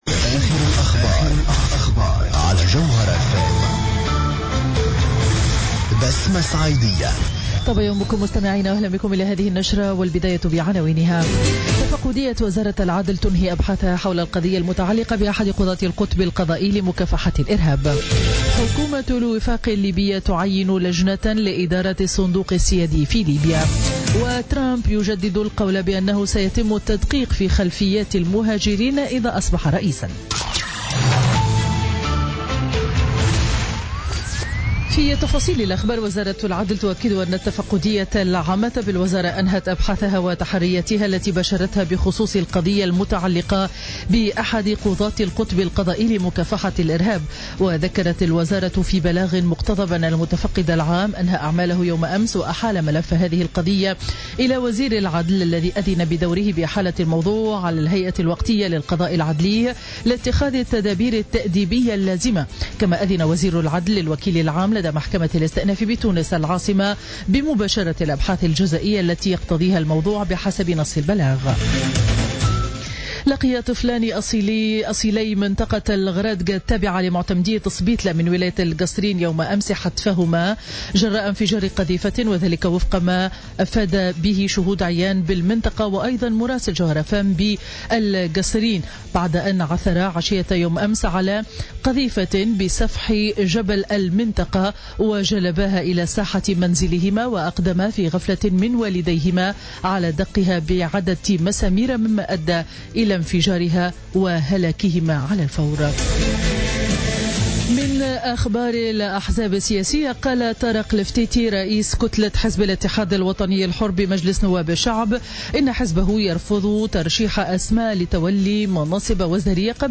نشرة أخبار السابعة صباحا ليوم الثلاثاء 16 جويلية 2016